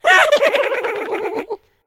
文件:Blues laughing.ogg